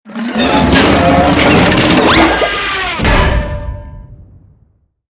CHQ_VP_big_death.mp3